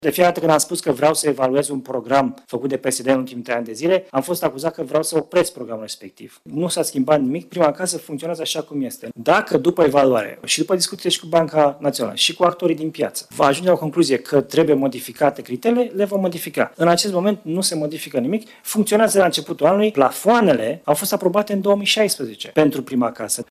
Programul Prima Casă va funcționa în continuare, iar guvernul nu a modificat suma de bani destinată acestui program – a spus ministrul Finanțelor: